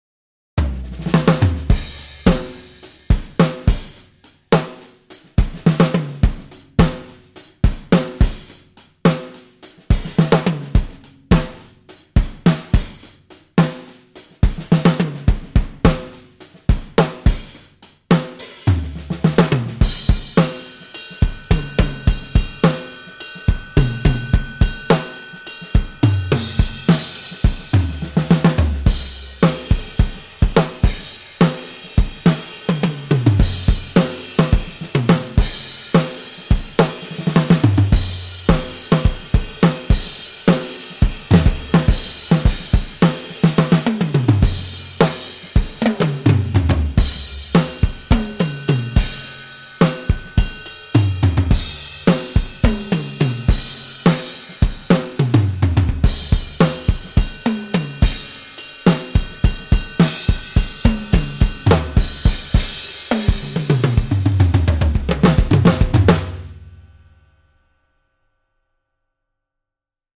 六 个原声鼓套件和超过 400 种经典鼓机声音
在英国伦敦的英国格罗夫工作室拍摄
为了增加更加有机和正确的时期风味，所有鼓和机器在数字转换之前都被记录到磁带上。